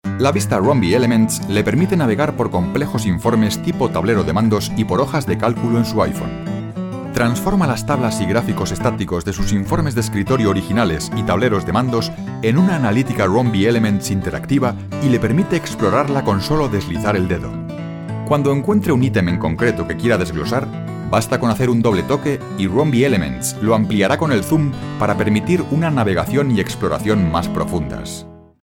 Excellent corporate voice. Warm, Kind, Friendly and Expressive.
kastilisch
Sprechprobe: eLearning (Muttersprache):